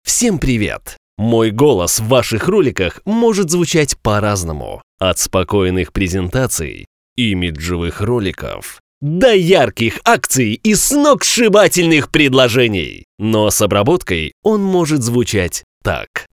Молодой динамичный голос, но могу быть серьёзным :) График работы: Пн.
Тракт: микрофон: Neumann TLM 103,предусилитель: dbx 376,конвертор: RME Babyface Pro